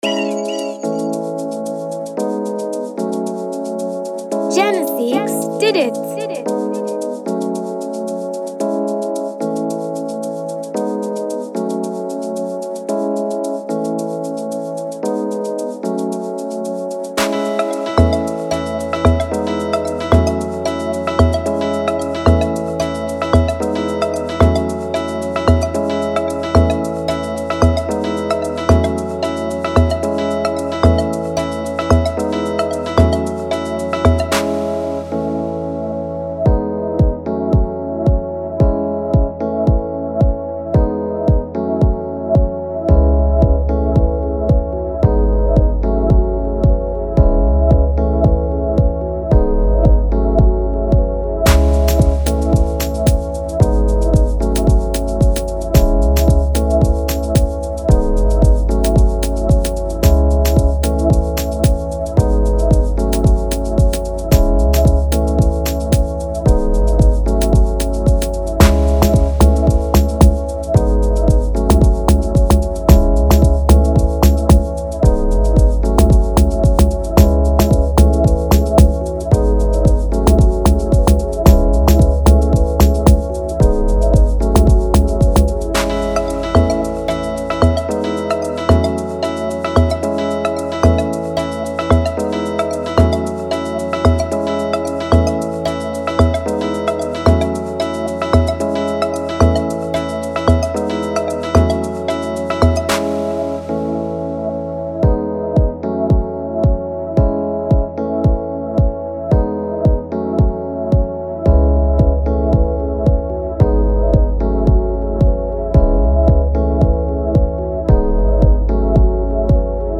Amapiano instrumental beat